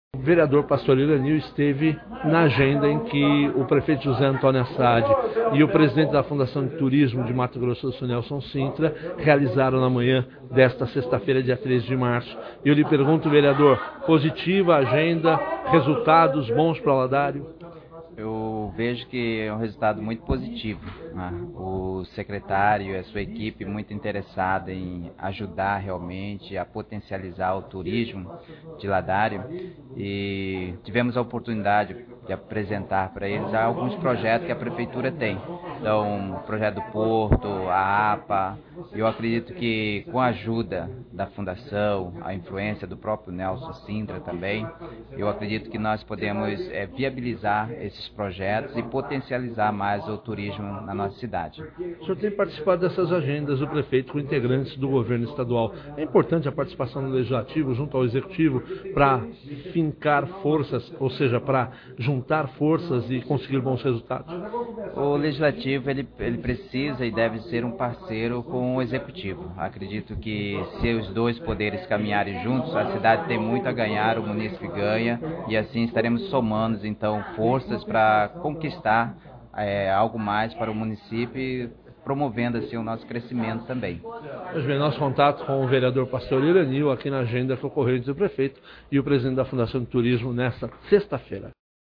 Entrevistas
VEREADOR PASTOR IRANIL